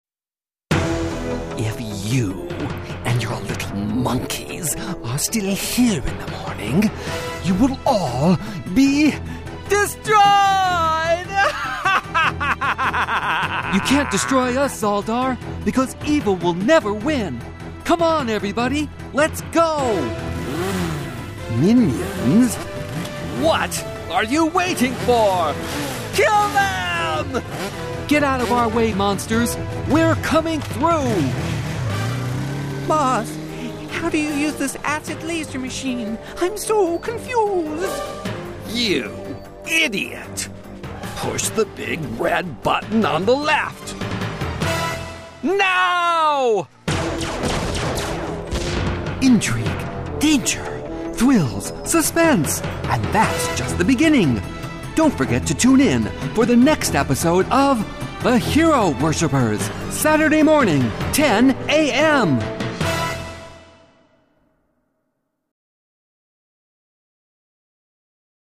英語/北米・オセアニア地方 男性